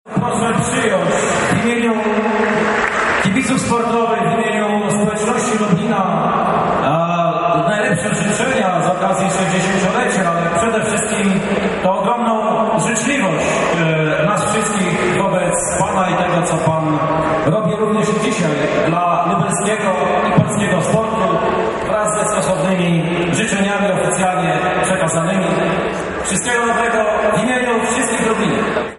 Impreza, na którą przyszło ponad 3 tys. osób, rozpoczęła się od życzeń i prezentów dla jubilata. Najlepsze życzenia w imieniu lublinian składał prezydent miasta Krzysztof Żuk:
Żuk-życzenia.mp3